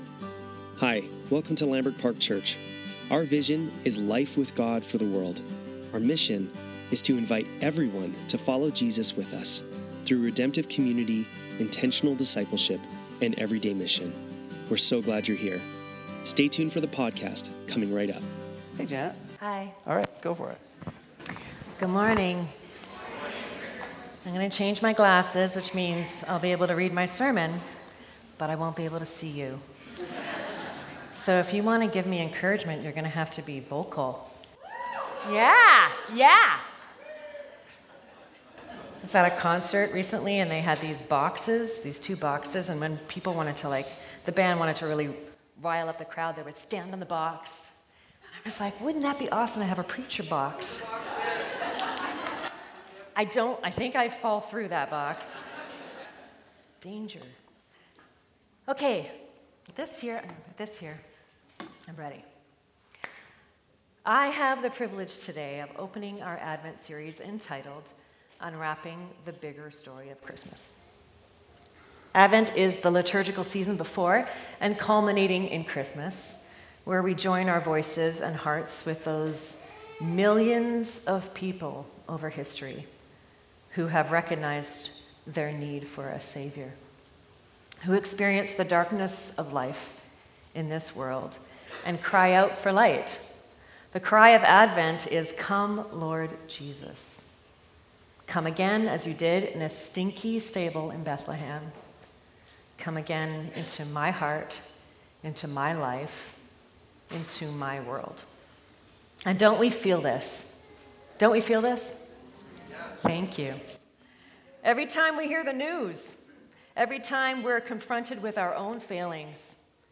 Lambrick Sermons | Lambrick Park Church
Sunday Service - December 7, 2025